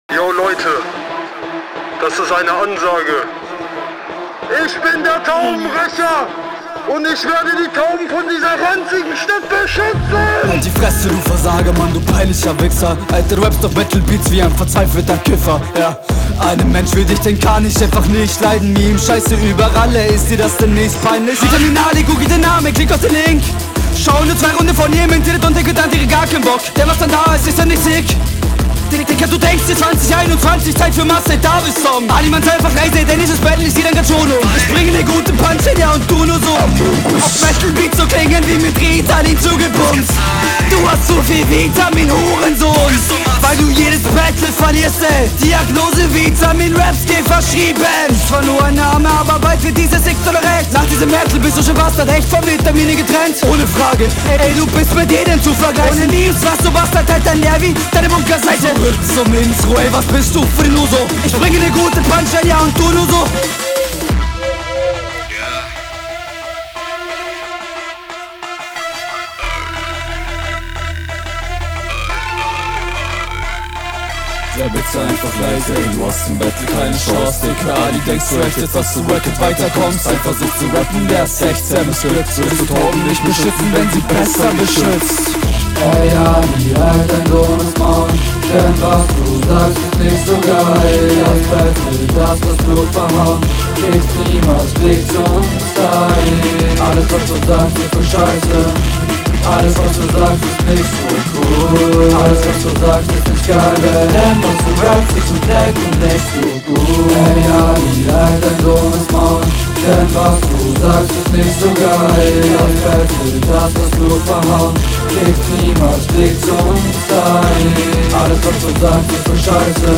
Flow: Geile Betonungen, doubletime teilweise bissl undeutlich aber klingt cool. Stimmiger Rap auf jeden.
Flowlich ist der Einstieg super gut. Die Pause bei dem ''ja'' ist super stylisch.
Flow: Flow ist cool, doch ich finde den Stimmeinsatz nicht gut.